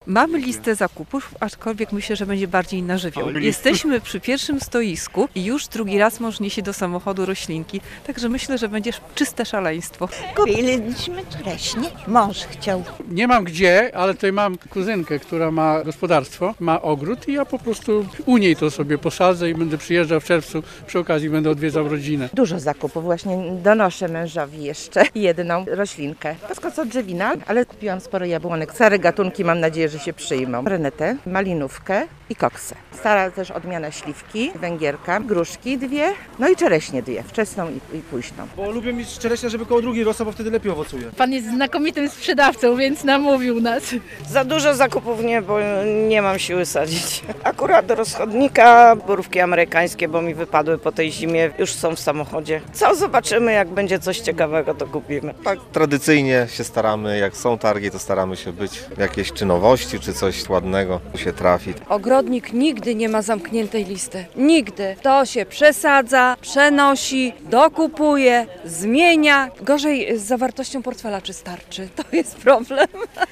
Wiadomości - Targi ogrodnicze w Szepietowie - moc sadzonek krzewów owocowych i ozdobnych